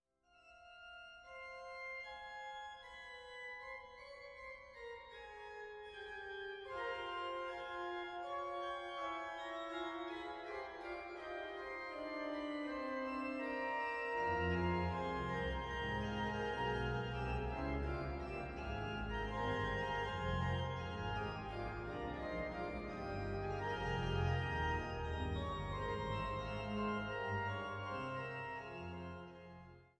Deshalb wurde diese Werkgruppe für die vorliegende Einspielung an der Hildebrandt-Orgel in Naumburg ausgewählt, da diese eine Manualverteilung auf Haupt- und Oberwerk sowie auf dem Rückpositiv hat.